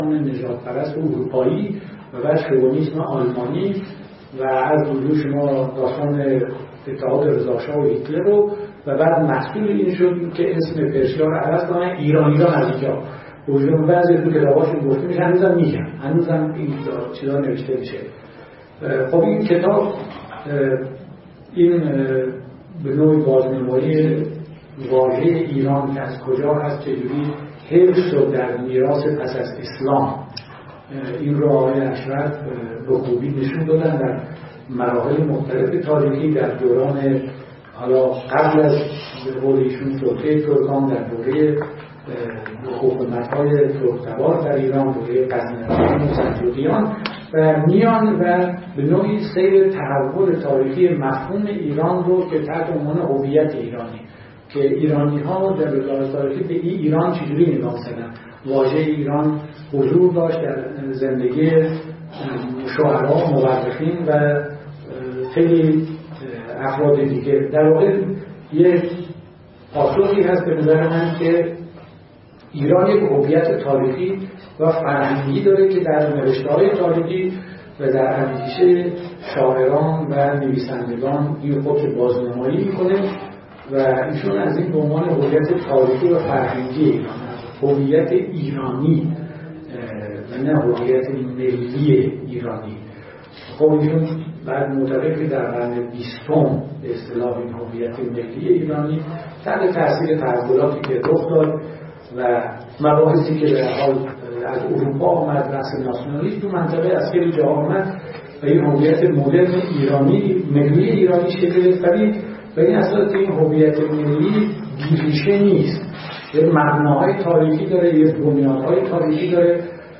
فرهنگ امروز: نخستین نشست از سلسله نشست‌های تاریخ نظریه ای/مفهومی و جامعه شناسی تاریخی ایران با موضع بررسی انتقادی کارنامه علمی احمد اشرف به همت فصلنامه مردم نامه و با همکاری و همراهی انجمن ایرانی تاریخ، انجمن جامعه شناسی ایران، خانه اندیشمندان علوم انسانی، انجمن علوم سیاسی ایران، دانشکده علوم اجتماعی دانشگاه تهران وموسسه نگارستان اندیشه در روز ۱۵ اردیبهشت ماه۱۳۹۷در سالن علی شریعتی دانشکده علوم اجتماعی دانشگاه تهران برگزار شد.